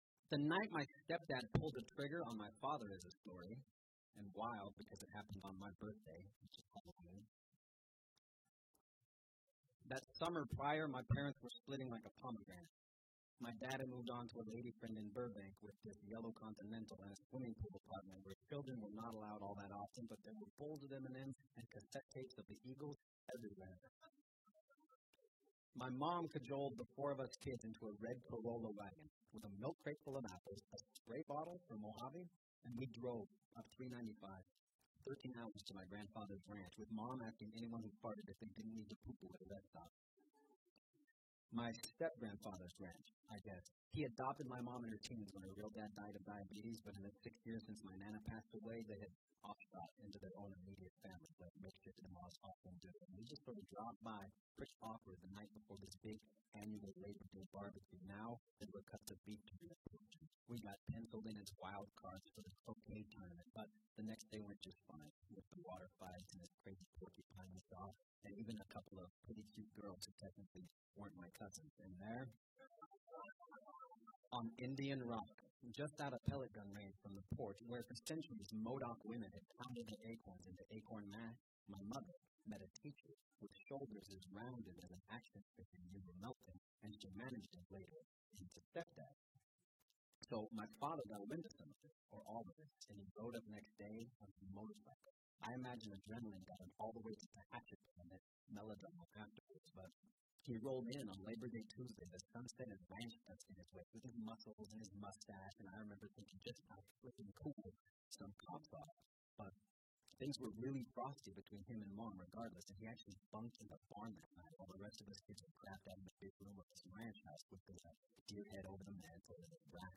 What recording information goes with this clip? AUDIO POEMS These tracks were recorded live at New York's Bowery Poetry Club .